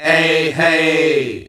EHEY    E.wav